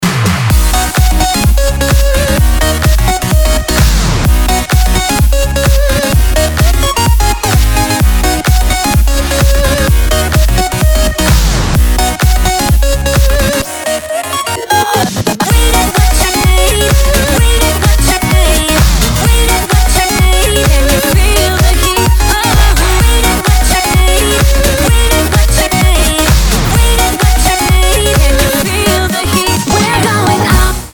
• Качество: 320, Stereo
dance
Electronic
EDM
Electronica
быстрые
electro house
Позитивный и очень энергичный клубный расколбас))